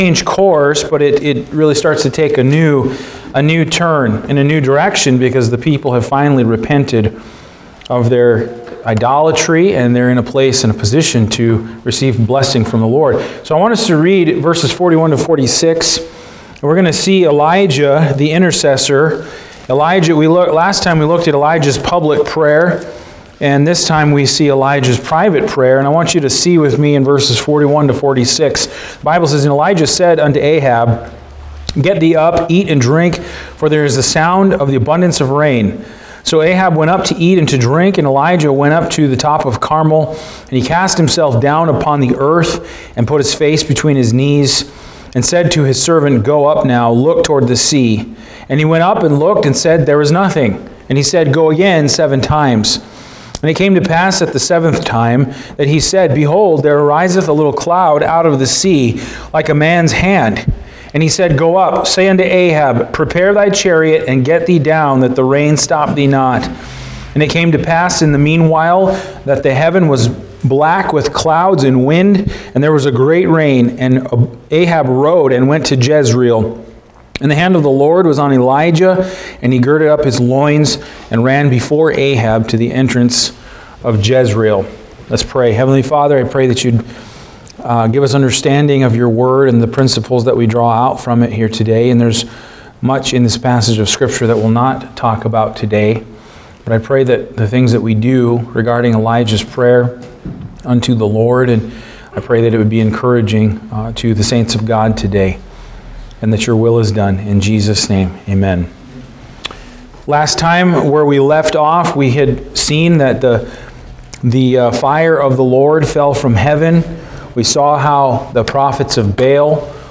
Passage: I Kings 18:41-46 Service Type: Sunday Afternoon